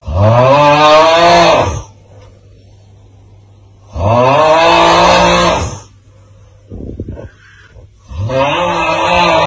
auughhh.mp3